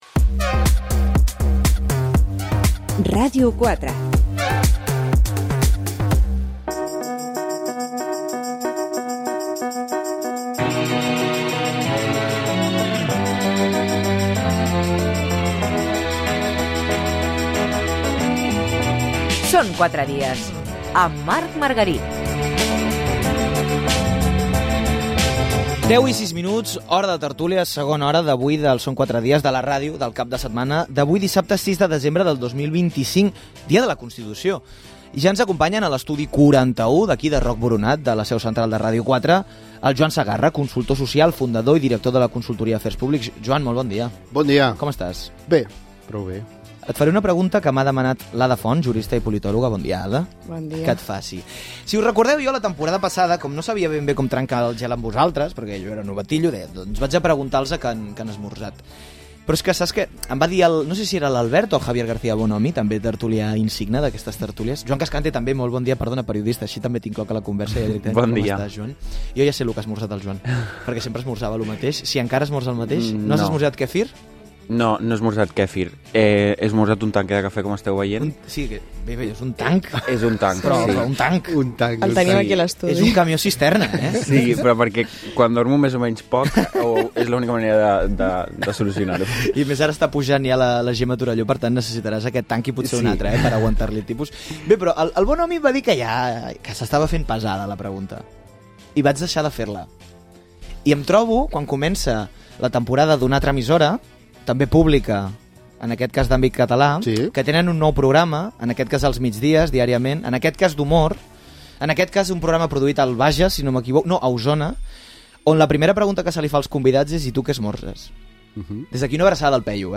Tertúlia setmanal